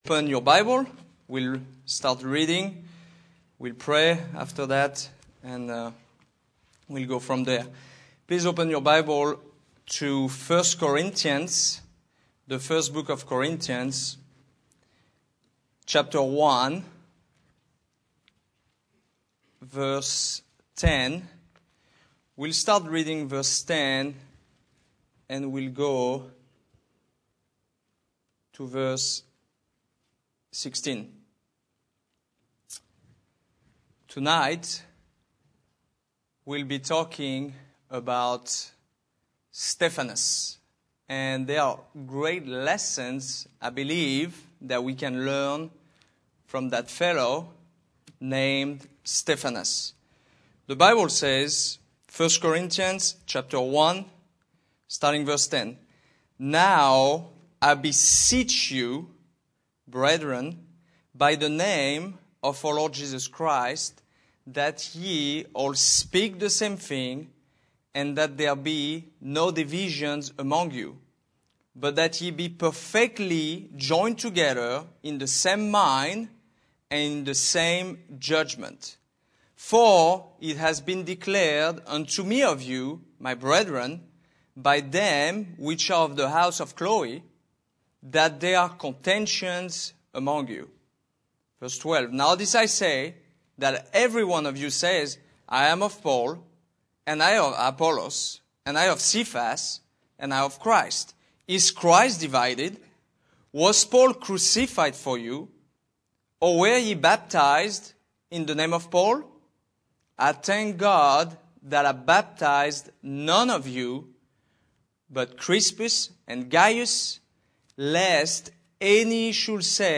Sermons https